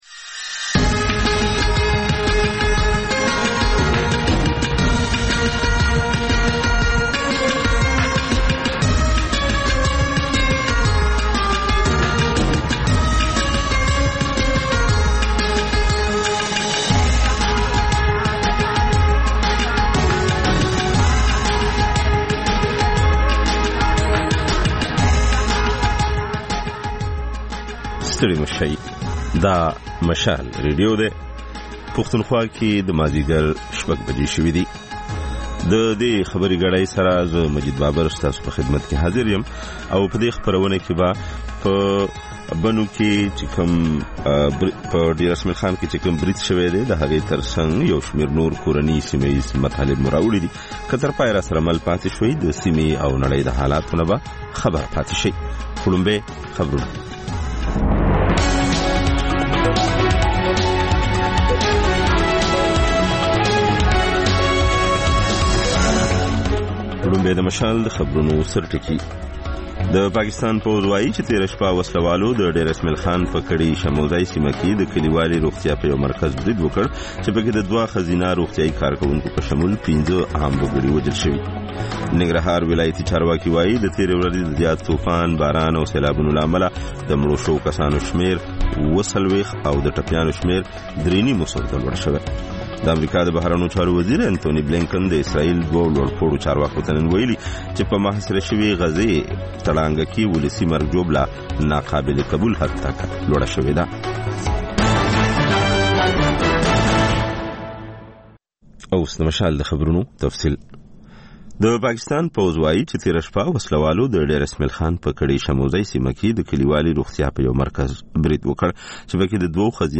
د مشال راډیو د ۱۴ ساعته خپرونو دویمه او وروستۍ خبري ګړۍ. په دې خپرونه کې تر خبرونو وروسته بېلا بېل سیمه ییز او نړیوال رپورټونه، شننې، مرکې، کلتوري او ټولنیز رپورټونه خپرېږي.